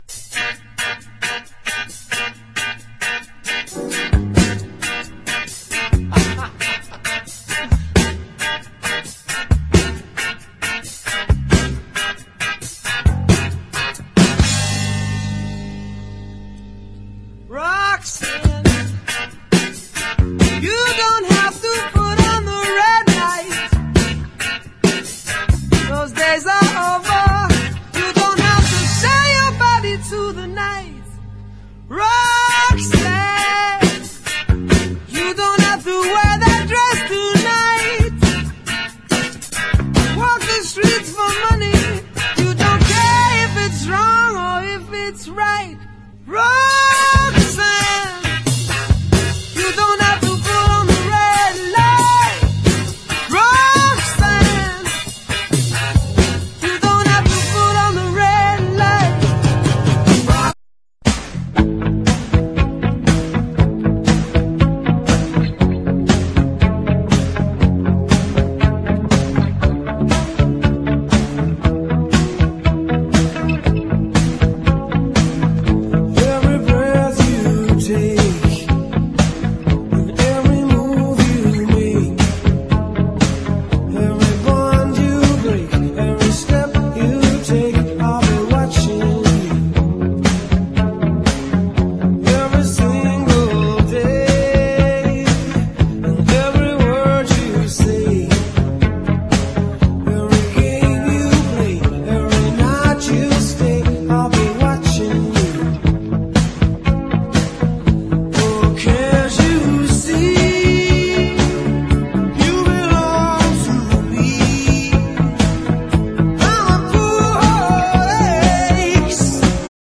¥1,180 (税込) ROCK / 80'S/NEW WAVE.
ドカッと打付けるハイエッジなリズムにフェアライト社サンプラーから次々と繰り出されるサンプリング音の連射に即倒！